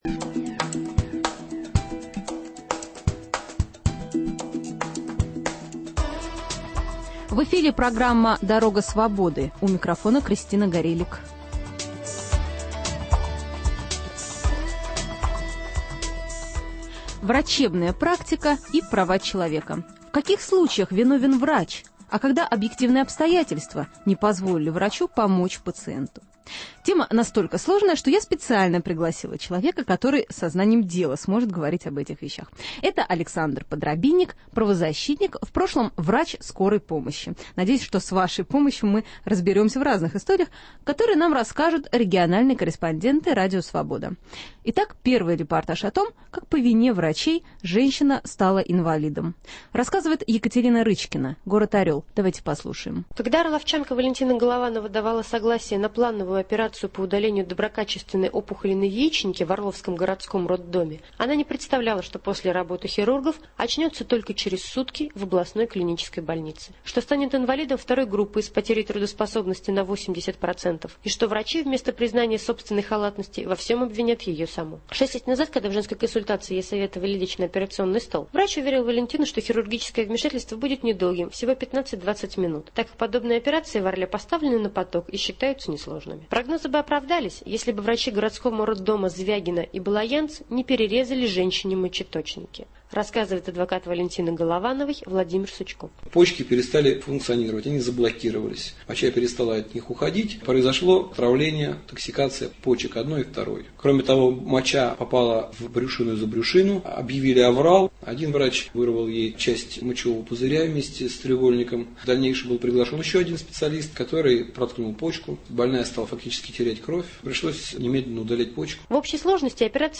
Репортажи из регионов комментирует правозащитник Александр Подрабинек, в прошлом - врач "скорой помощи".